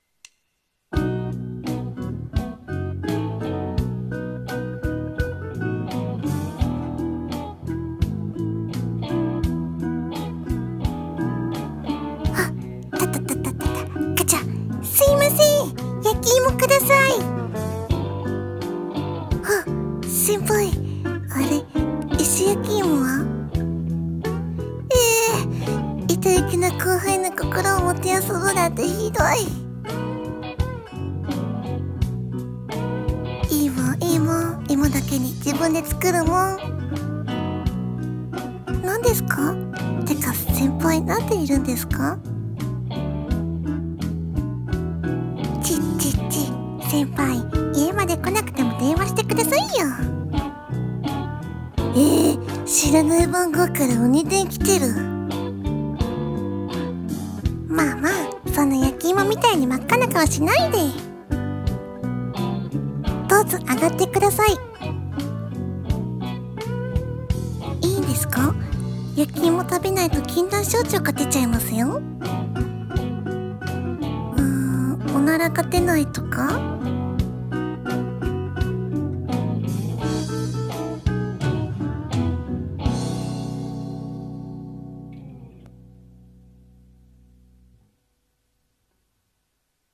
お笑い声劇